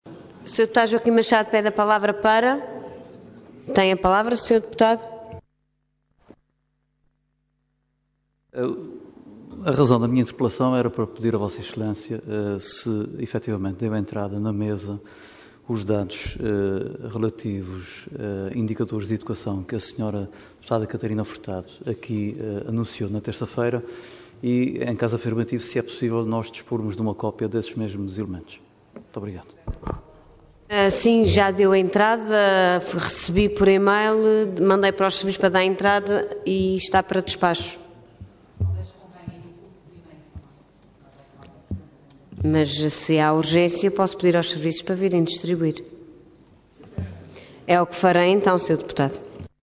Detalhe de vídeo 11 de dezembro de 2014 Download áudio Download vídeo Processo X Legislatura Maus resultados obtidos pelo sistema educativo regional no âmbito dos exames nacionais Intervenção Interpelação à Mesa Orador Joaquim Machado Cargo Deputado Entidade PSD